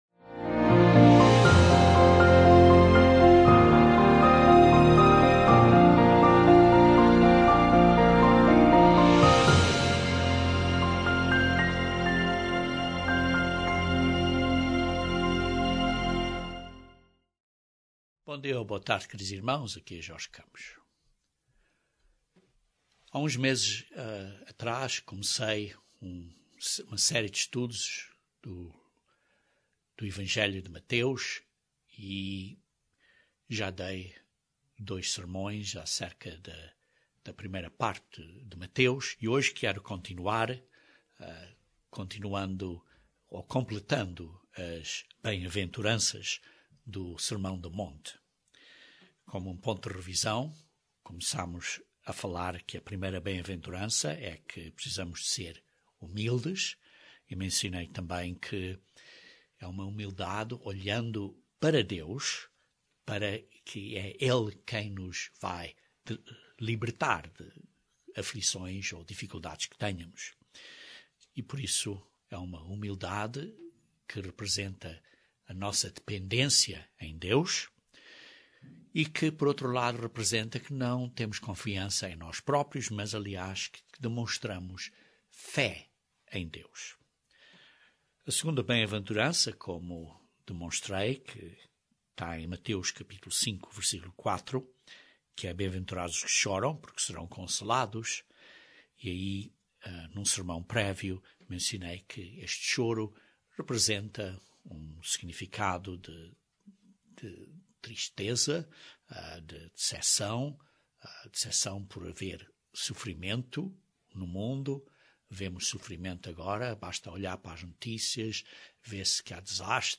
Este sermão continua o estudo das bem-aventuranças que Cristo mencionou no sermão do monte